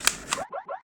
laserPumpEmpty.ogg